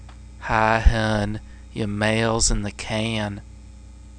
Mail sounds
(Accented male voice),"Hi hon, your mail's in the can.."